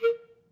Clarinet
DCClar_stac_A#3_v2_rr2_sum.wav